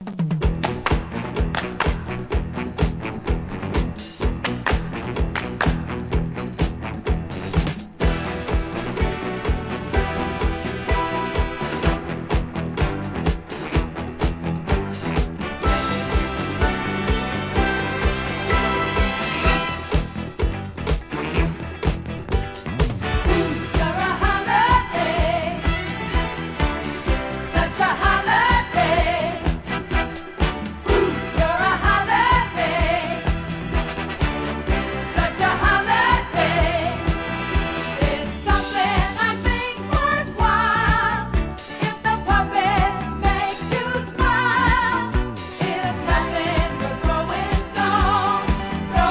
¥ 440 税込 関連カテゴリ SOUL/FUNK/etc...